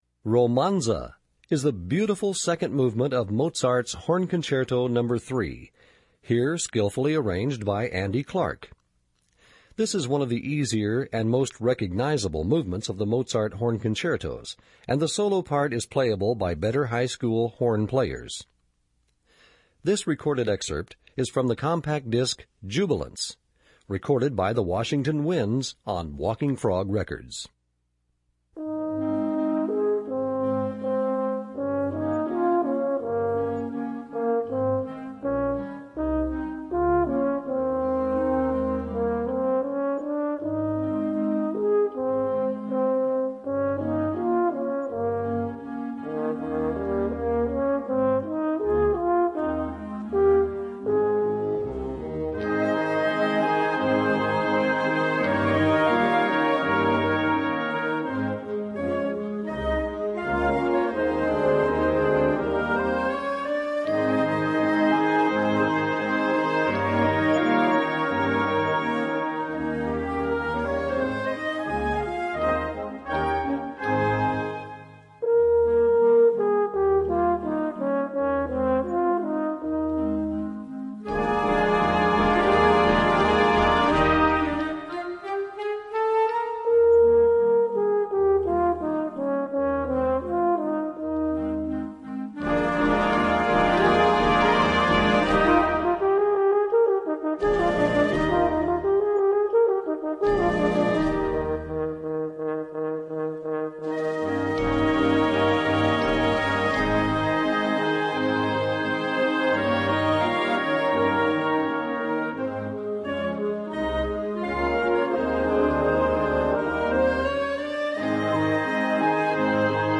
Voicing: French Horn w/ Band